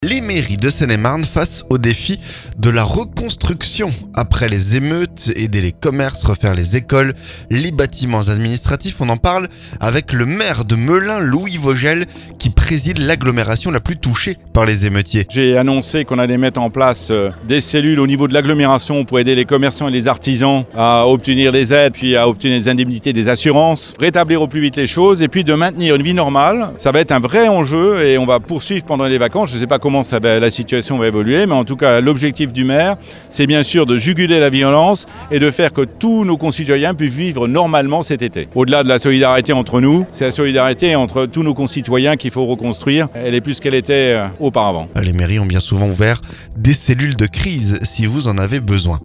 Les mairies de Seine-et-Marne face au défi de la reconstruction, après les émeutes. Aider les commerces, refaire les écoles, les bâtiments administratifs... On en parle avec le maire de Melun, Louis Vogel, qui préside l'agglomération la plus touchée par les émeutiers.